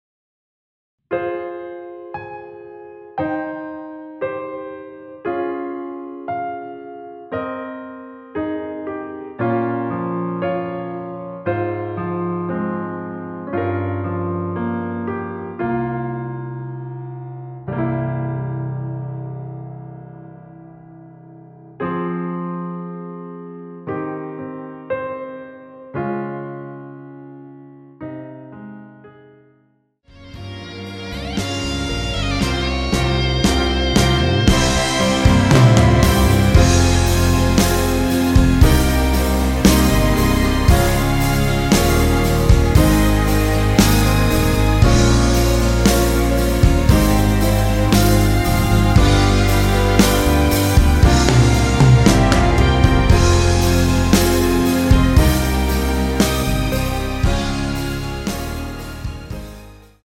원키에서(-2)내린 MR입니다.
Fm
앞부분30초, 뒷부분30초씩 편집해서 올려 드리고 있습니다.